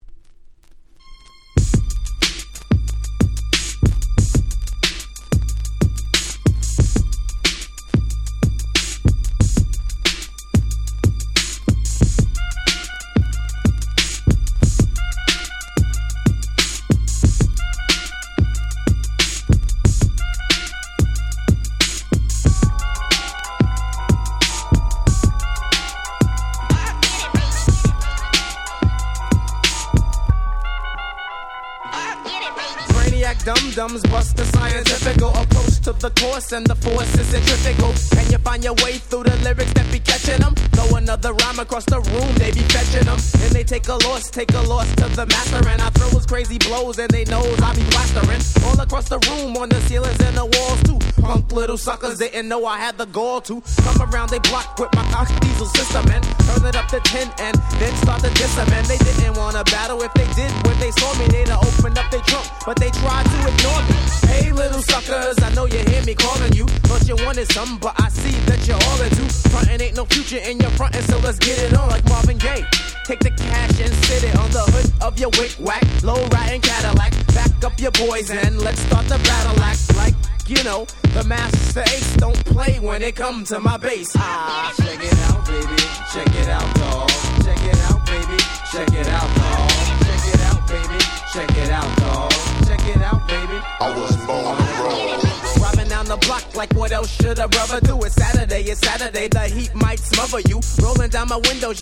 94' Super Nice Hip Hop !!
シンプルなBeatがグイグイくるSuper 90's Hip Hop Classics !!